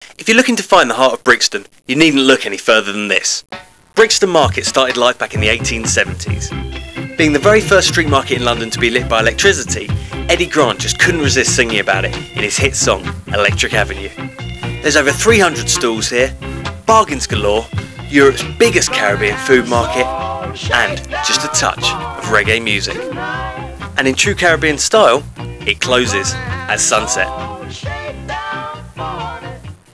Travel documentary voice over